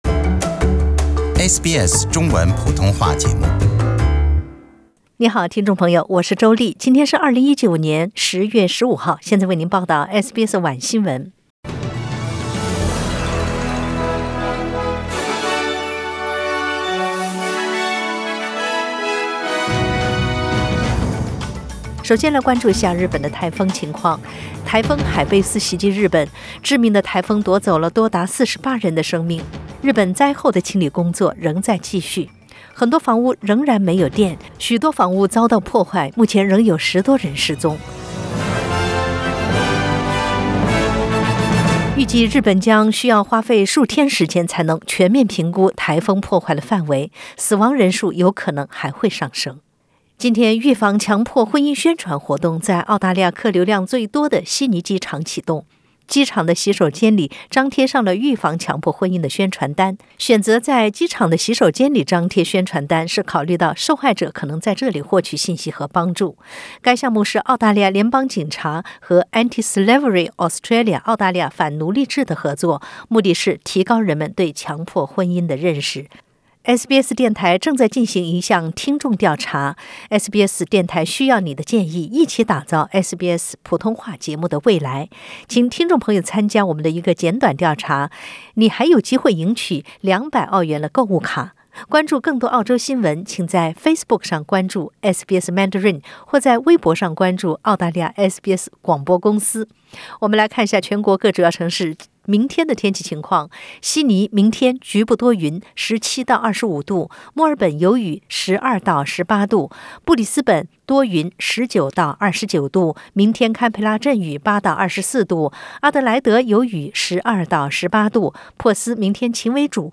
SBS 晚新闻 （10月15日）